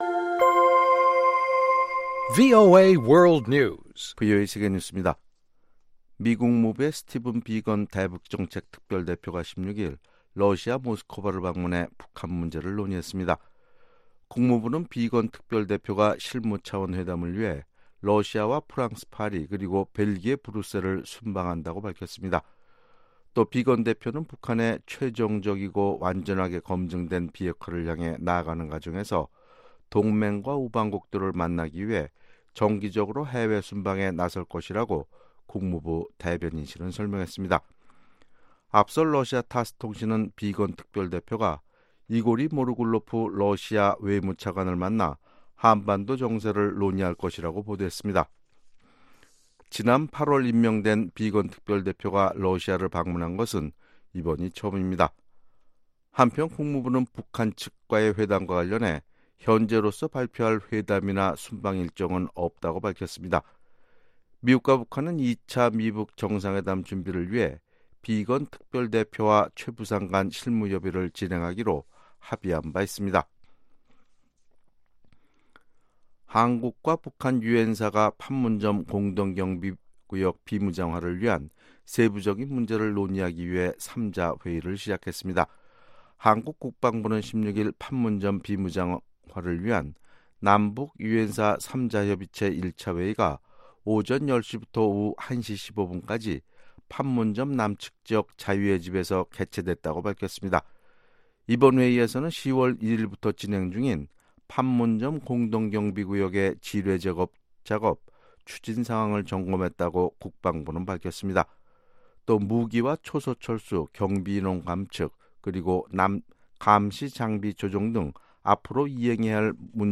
VOA 한국어 아침 뉴스 프로그램 '워싱턴 뉴스 광장' 2018년 10월 17일 방송입니다. 도널드 트럼프 대통령은 북한 문제는 복잡하면서도 매우 잘 진행되고 있다고 밝혔습니다. 미 하원 의원들이 도널드 트럼프 대통령에게 미국 내 한인 이산가족 상봉을 추구하는 서한을 보냈습니다.